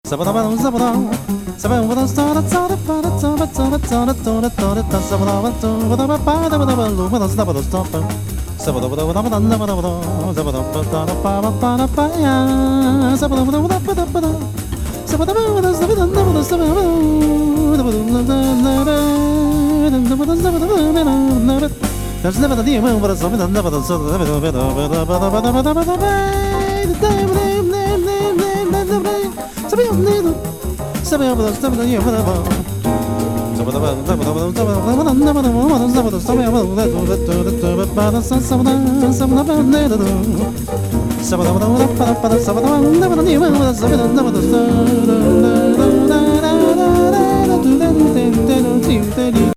極上コーラス・グループ